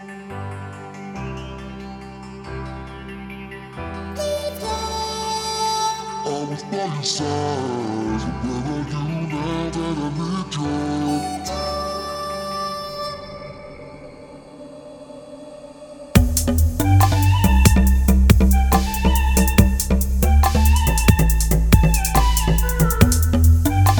no Backing Vocals R'n'B / Hip Hop 4:02 Buy £1.50